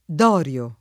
dorio [ d 0 r L o ]